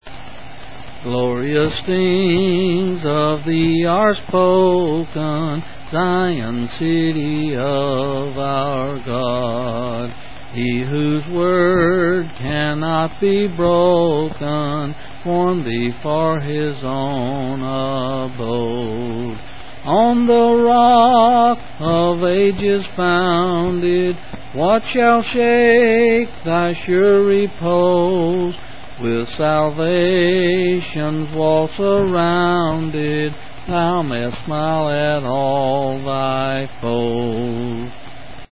8's 7's Rippon